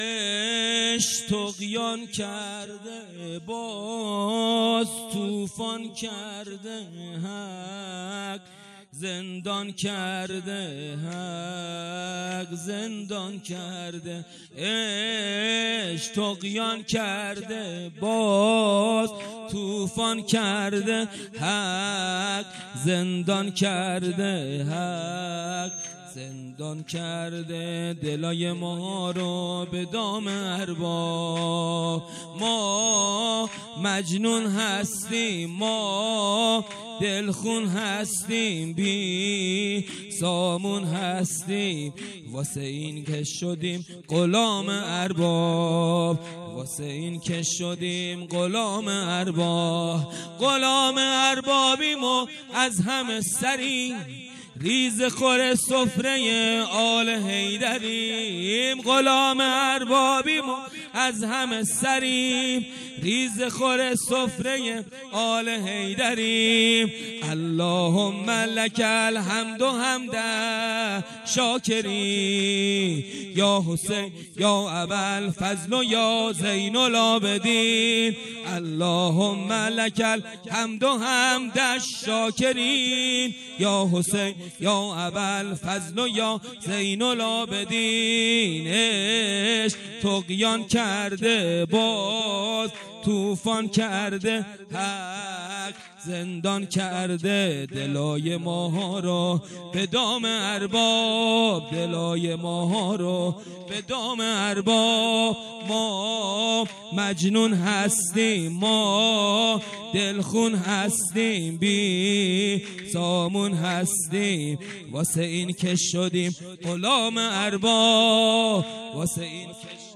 خیمه گاه - هیئت ذبیح العطشان کرمانشاه - ولادت امام حسین(ع)-سرود امام حسین(ع)
هیئت ذبیح العطشان کرمانشاه